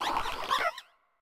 Cri de Terracool dans Pokémon Écarlate et Violet.